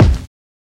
KICK RUGGED III.wav